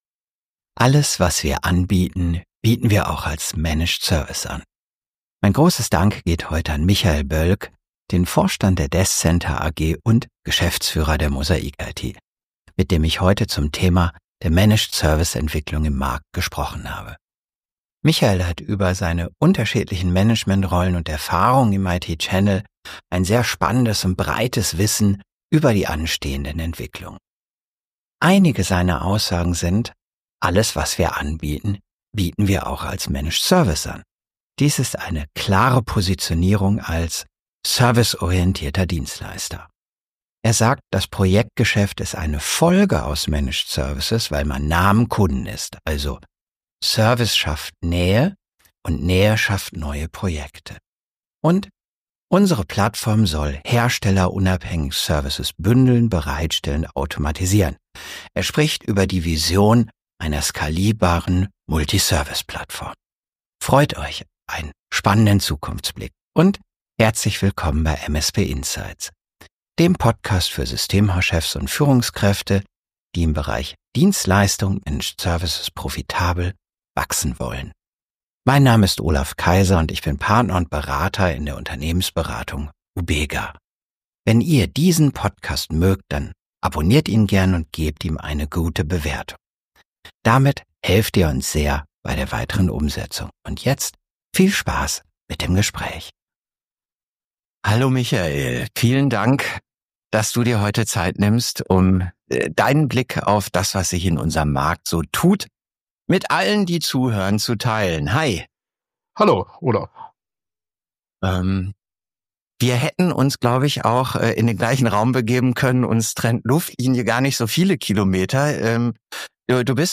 Vor kurzem durfte ich mit zwei tollen Experten live in Düsseldorf beim Channelpartnerkongress über dieses wichtige Zukunftsthema sprechen.